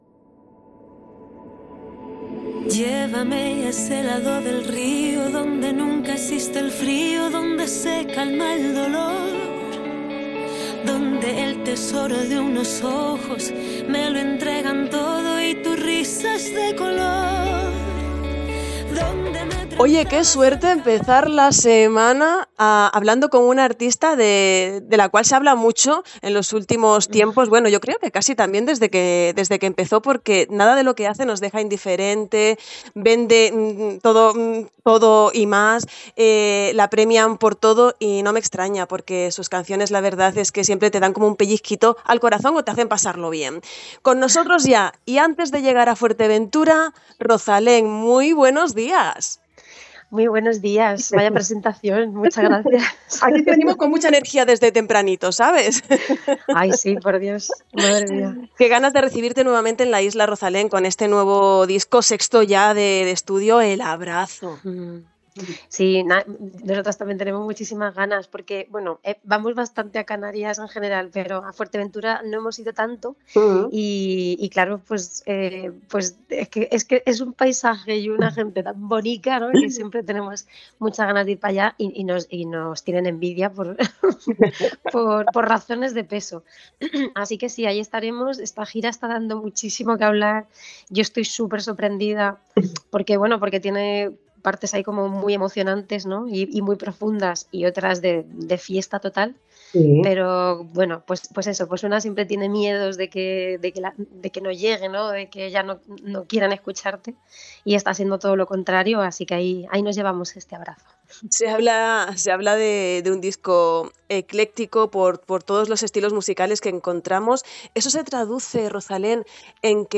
Entrevista-a-Rozalen.mp3